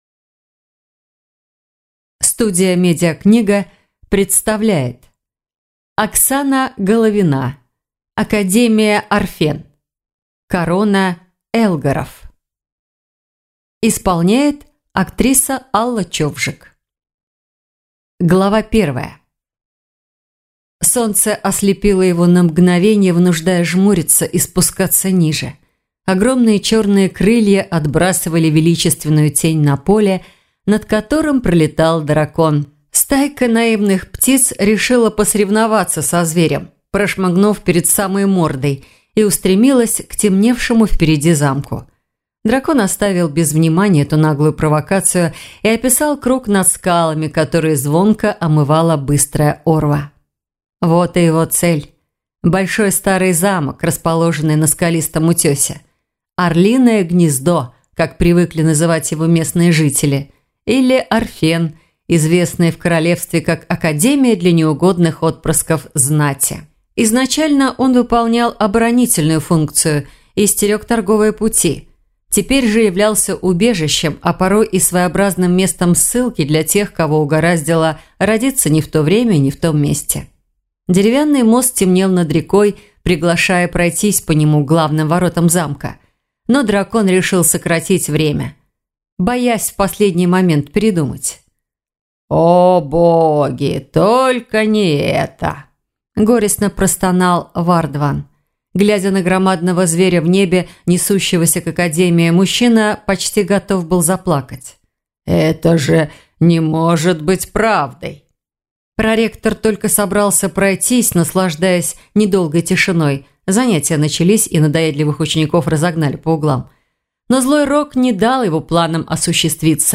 Аудиокнига Академия Арфен. Корона Эллгаров | Библиотека аудиокниг